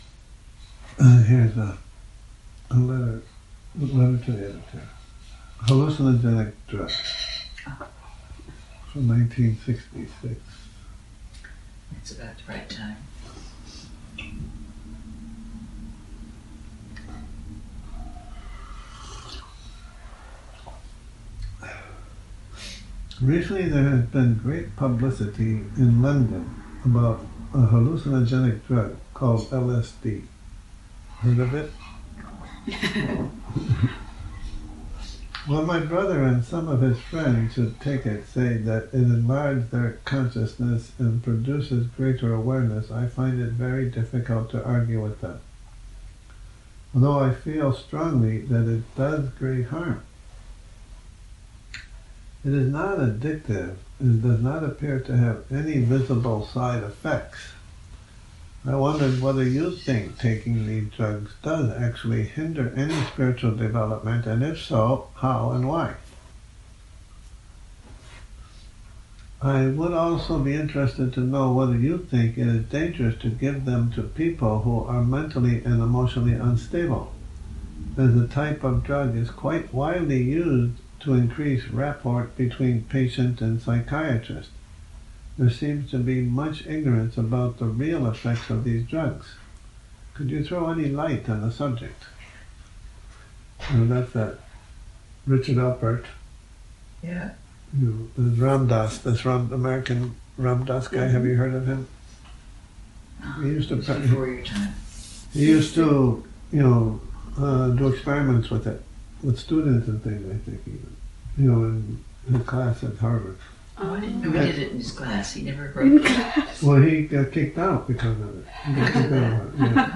Morning Reading, 19 Nov 2019